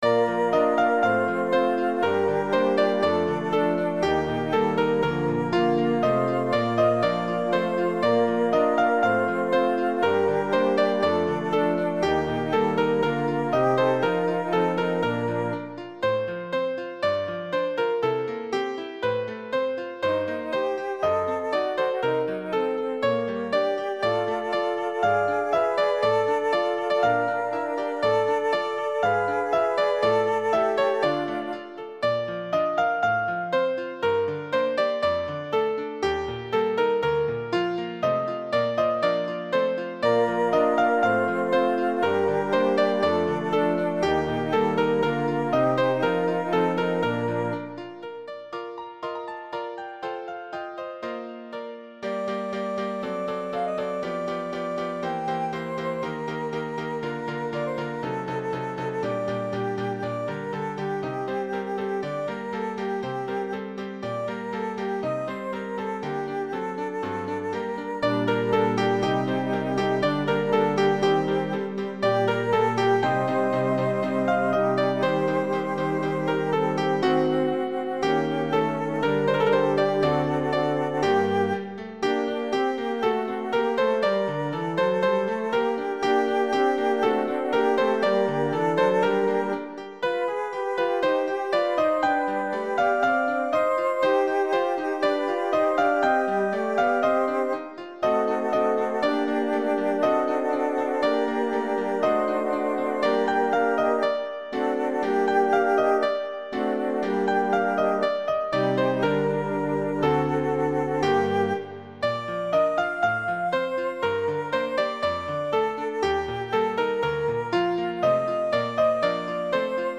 soprane 1
Neue-Liebe-neues-Leben-soprane-1.mp3